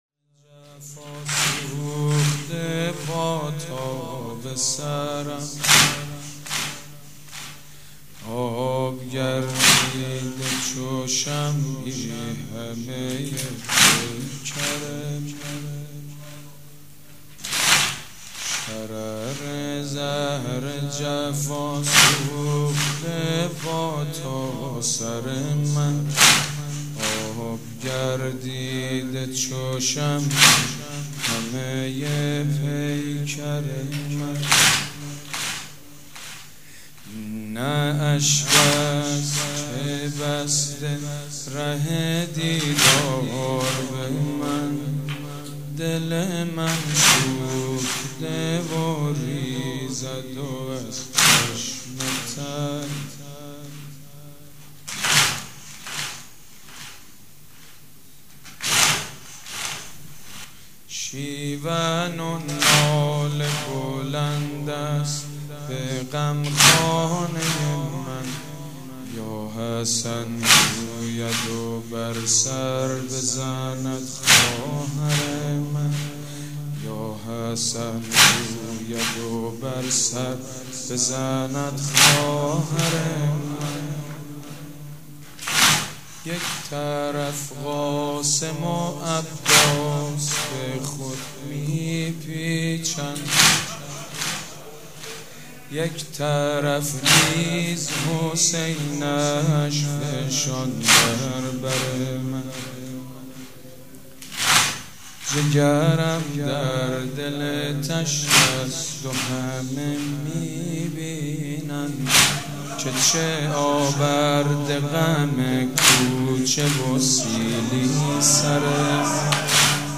مراسم عزاداری شب ‌پنجم